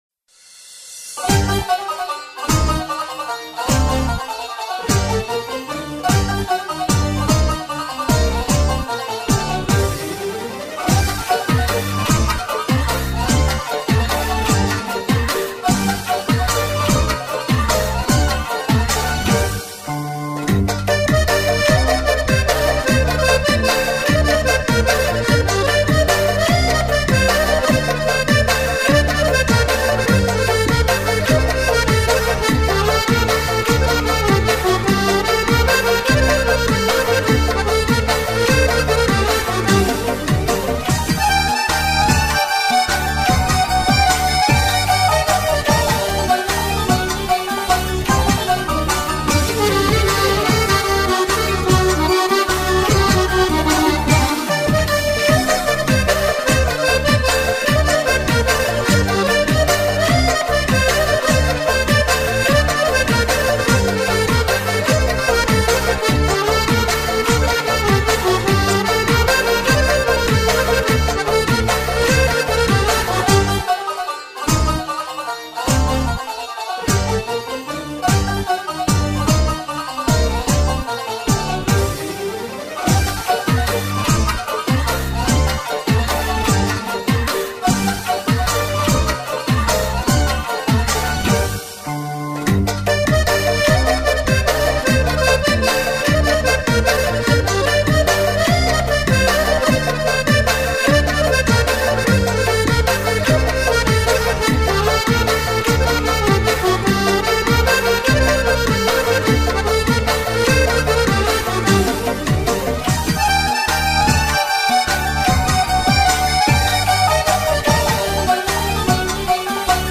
آهنگ بی کلام فصل بهار کودکانه برای عید نوروز